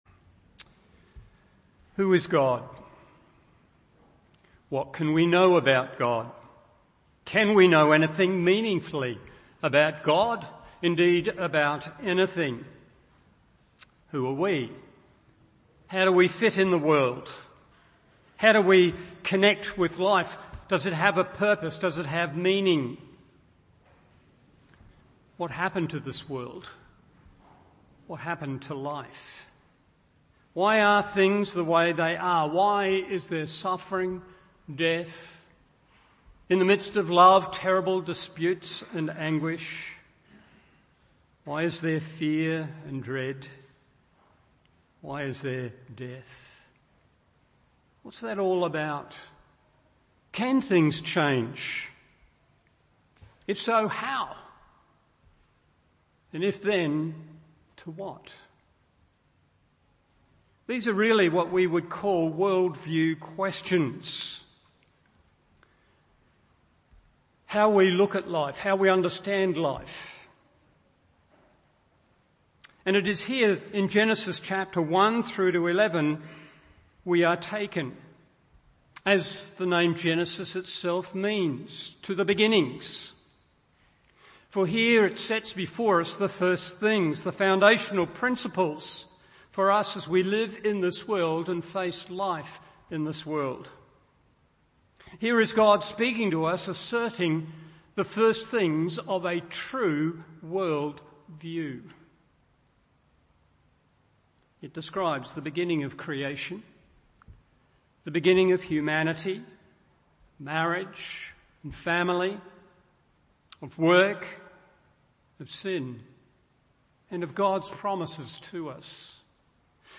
Morning Service Genesis 1:1 1.